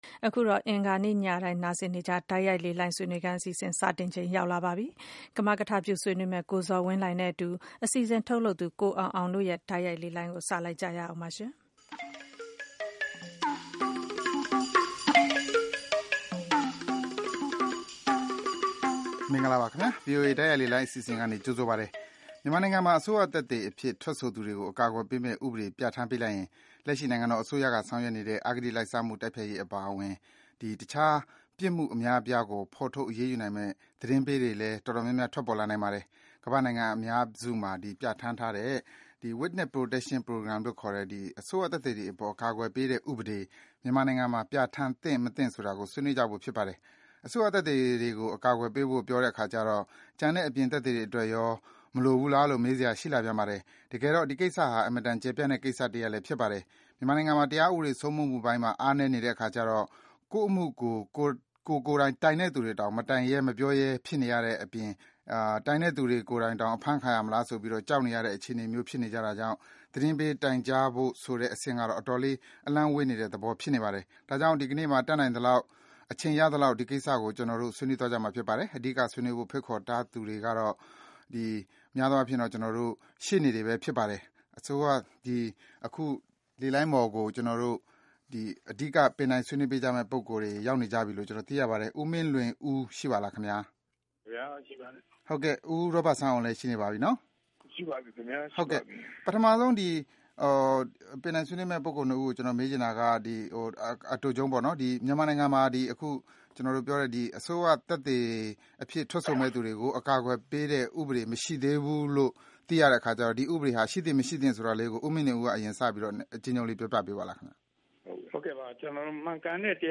မြန်မာနိုင်ငံမှာ အစိုးရသက်သေတွေကို အကာအကွယ်ပေးတဲ့ ဥပဒေ မရှိသေးတာကြောင့် ဒီ ဥပဒေ ပြဌာန်းသင့်၊ မသင့်ဆိုတာကို ဥပဒေပညာရှင်တွေနဲ့ ဆွေးနွေးထားပါတယ်။